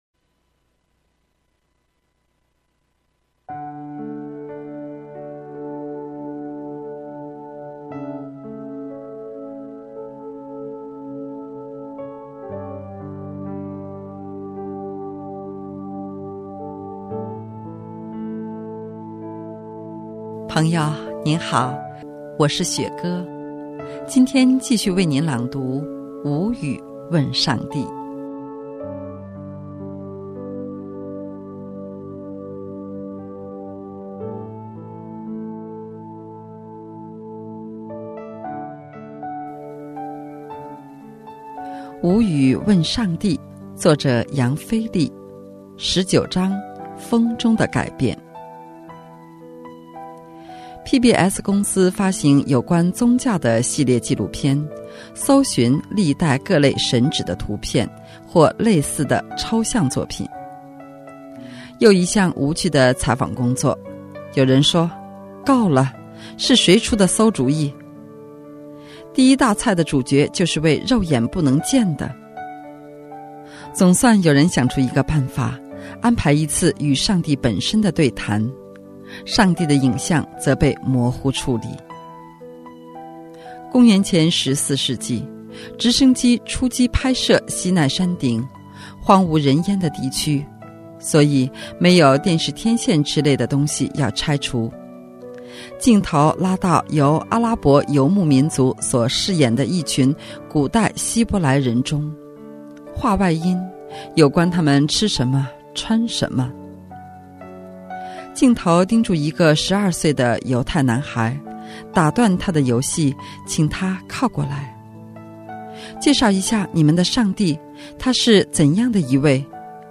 今天继续为您朗读《无语问上帝》